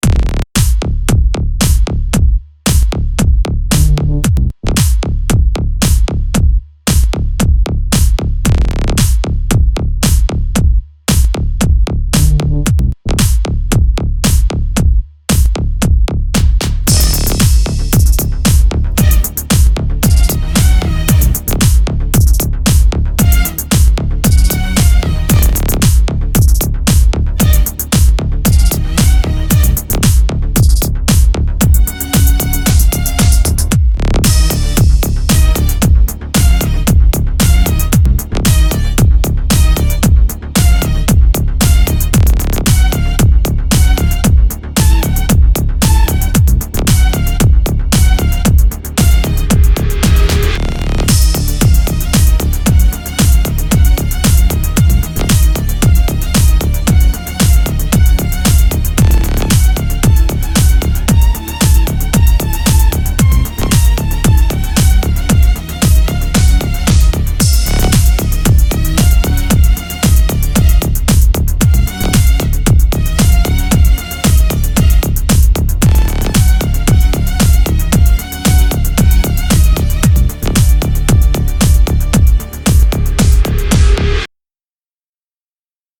tema dizi müziği, enerjik heyecan aksiyon fon müziği.